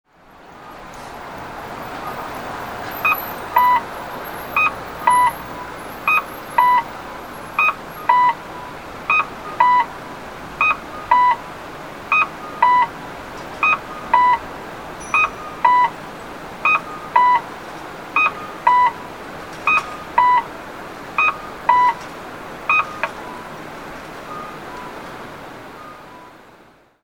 交通信号オンライン｜音響信号を録る旅｜山口県の音響信号｜[2022]中通商店街入口交差点
中通商店街入口交差点(山口県岩国市)の音響信号を紹介しています。